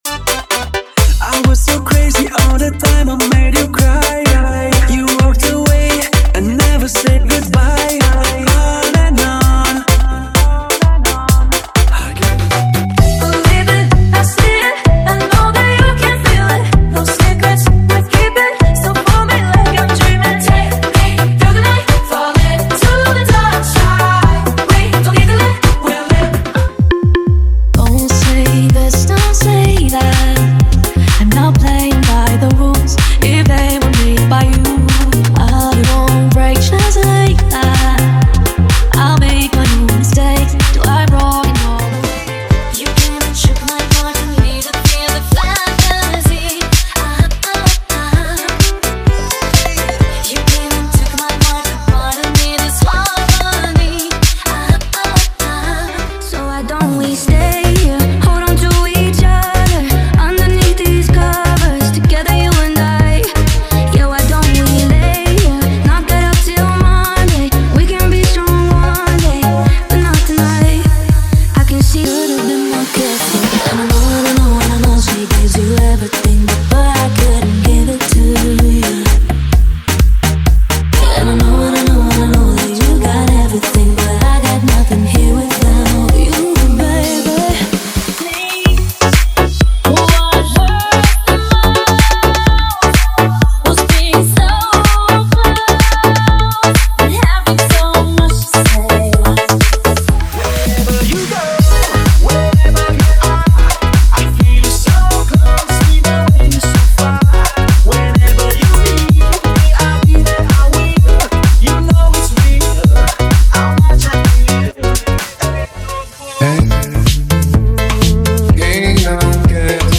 • Dance Comercial = 100 Músicas
• Sem Vinhetas